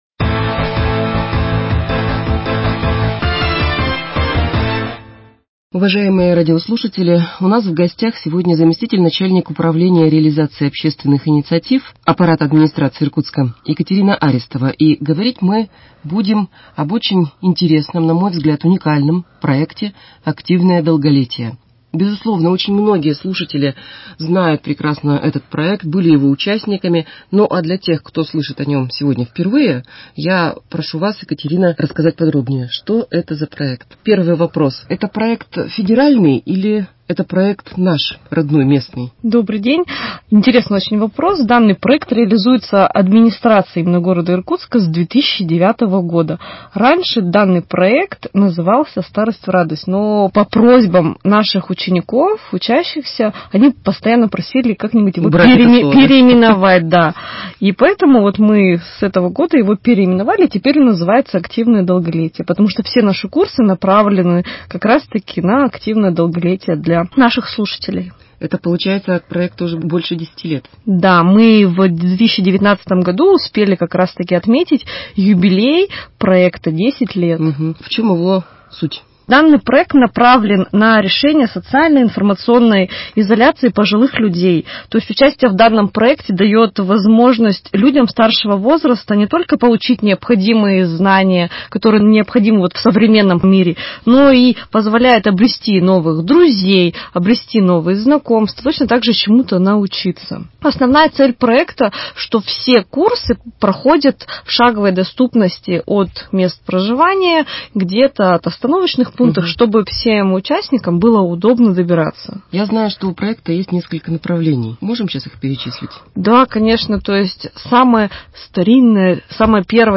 Актуальное интервью: Проект «Активное долголетие» 16.03.2021